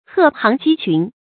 鶴行雞群 注音： ㄏㄜˋ ㄒㄧㄥˊ ㄐㄧ ㄑㄩㄣˊ 讀音讀法： 意思解釋： 同「鶴立雞群」。